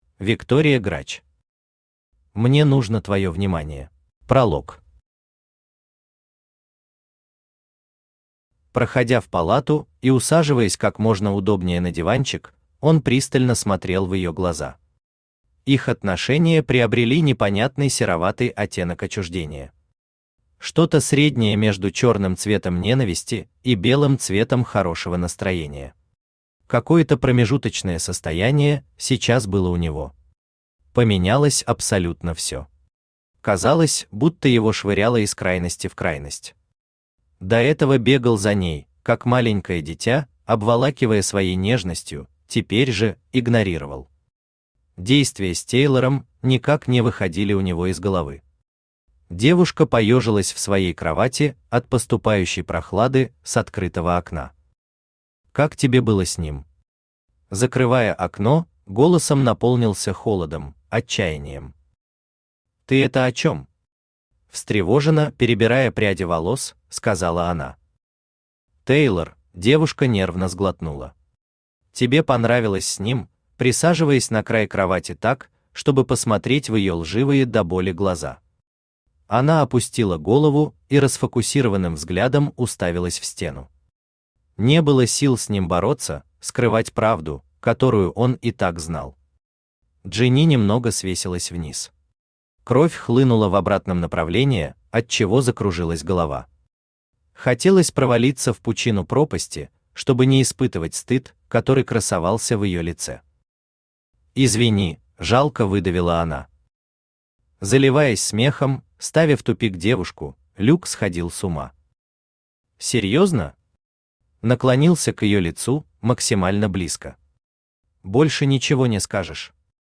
Аудиокнига Мне нужно твоё внимание | Библиотека аудиокниг
Aудиокнига Мне нужно твоё внимание Автор Виктория Грач Читает аудиокнигу Авточтец ЛитРес.